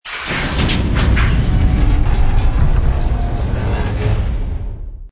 door_medium_open.wav